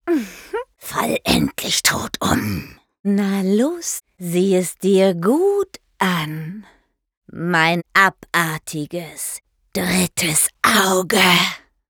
Synchron Anime (zwei Gesichter)